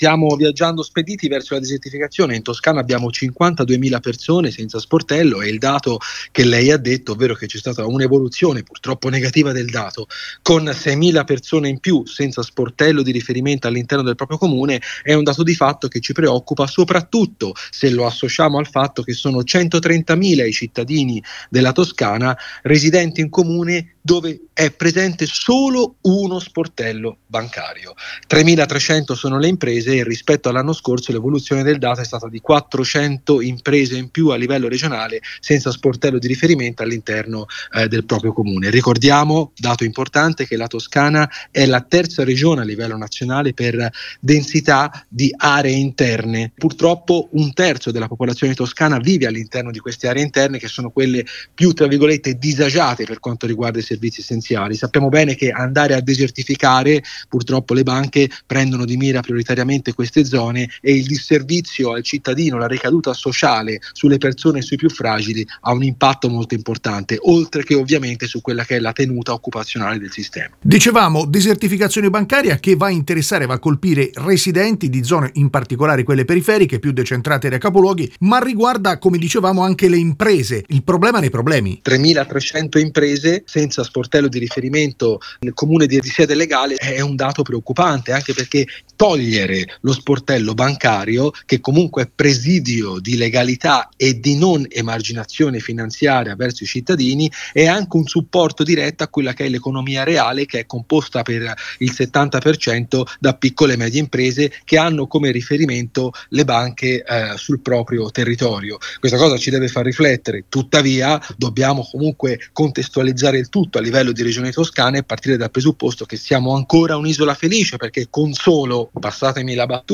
nel corso del notiziario di ieri sera di Radio Toscana